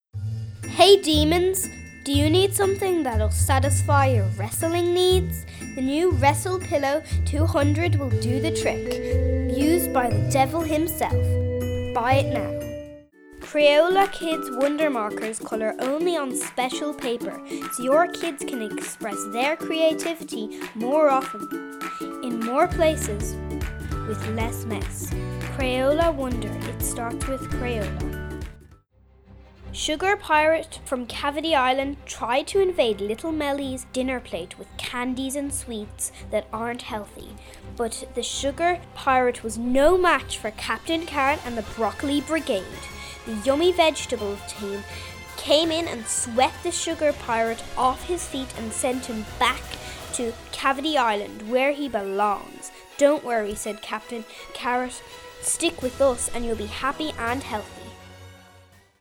Irish voice over artist
voice sampler